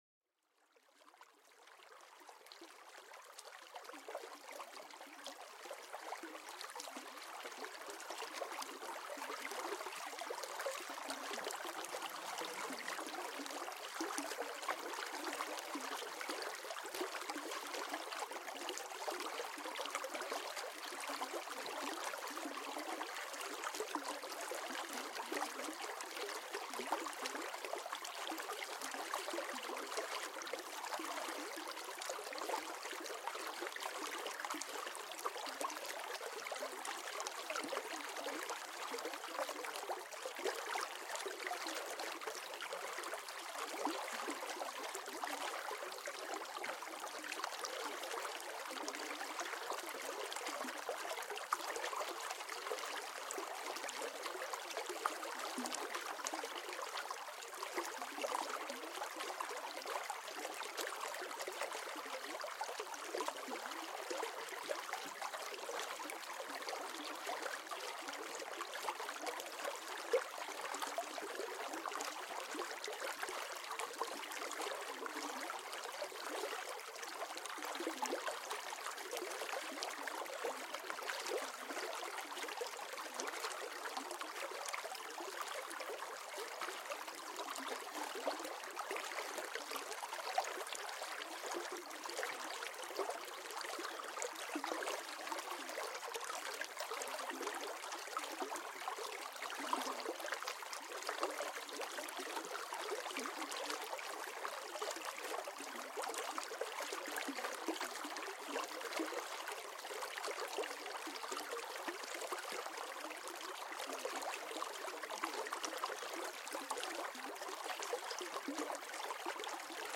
Déjate llevar por el suave murmullo de un arroyo cristalino, perfecto para una relajación total. Cada gota de agua y cada corriente te ayudarán a calmar tu mente y encontrar la paz interior.